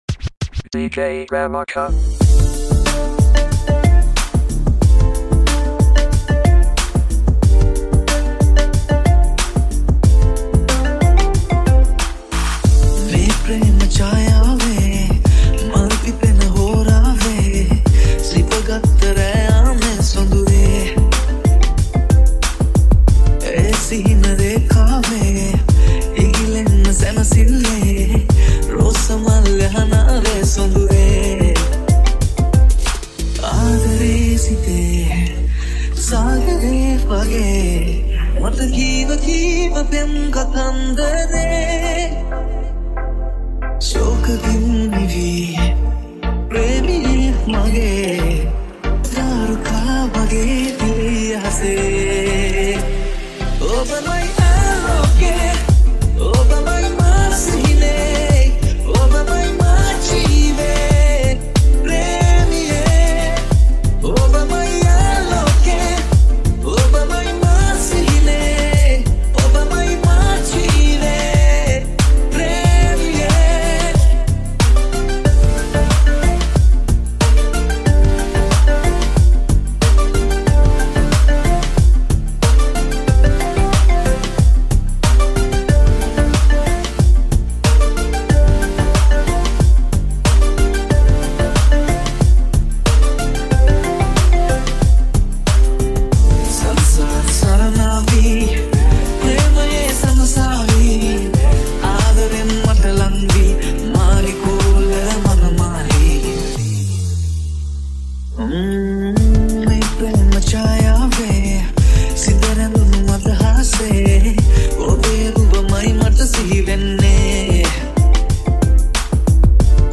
EDM Chillout Remake